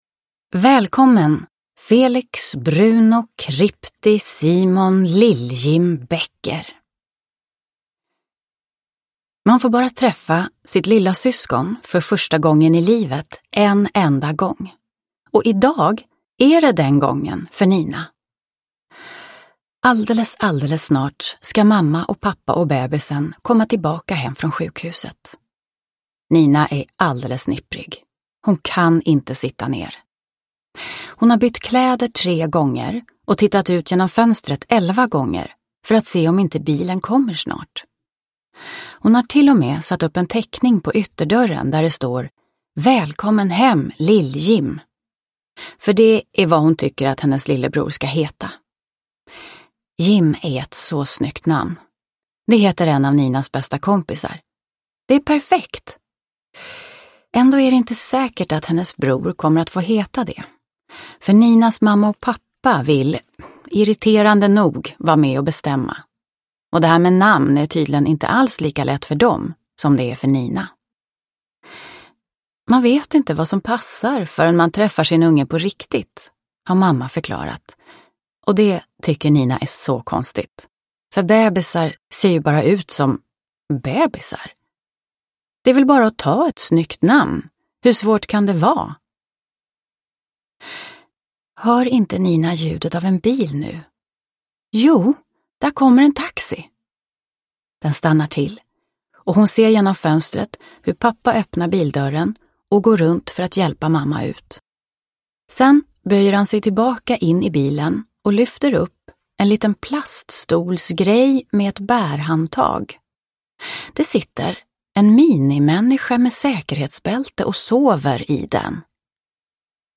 Ninas syskonbok – Ljudbok – Laddas ner